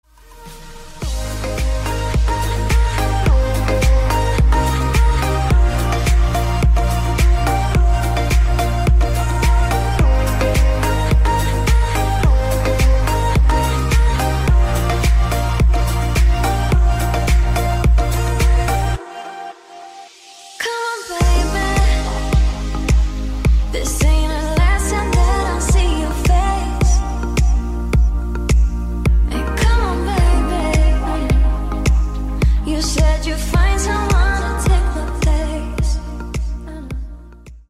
• Качество: 128, Stereo
dance
EDM
красивая мелодия
tropical house
красивый женский голос